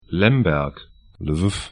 Lemberg 'lɛmbɛrk L’viv lvɪf uk Stadt / town 49°50'N, 24°00'E